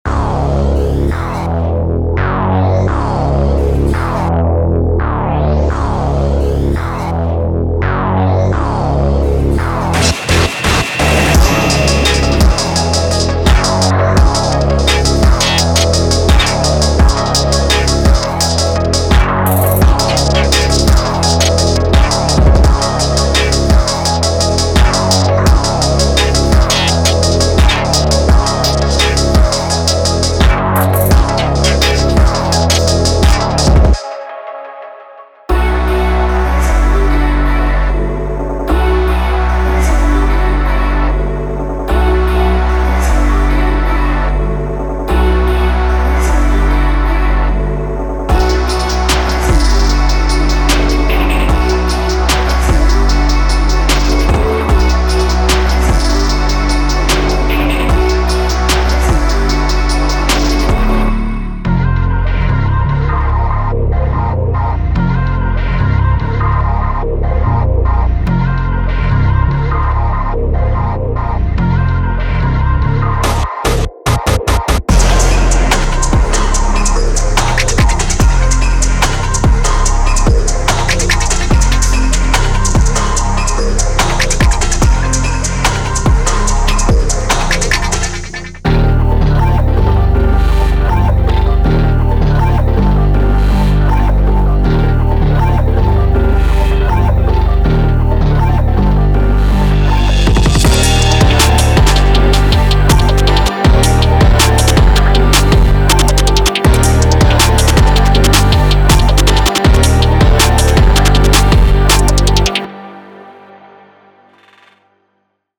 Type: Templates Samples
Heavy, distorted 808s
High-tempo, rage-style drum loops
Futuristic synth melodies
Glitch FX and transitions
Vocal chops